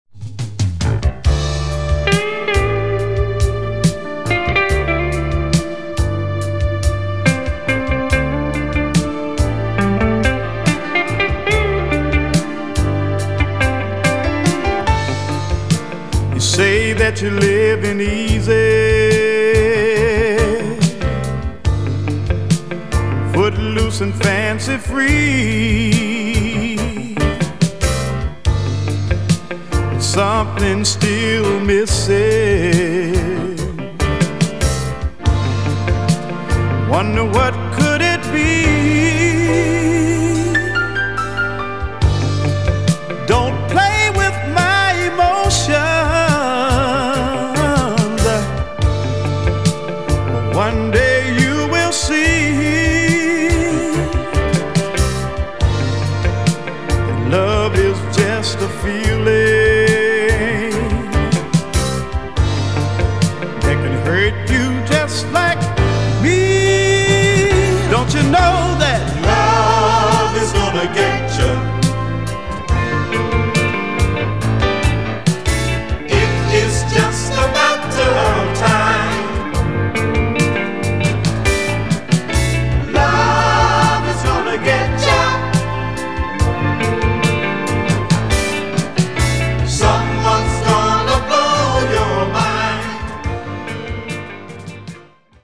a funk band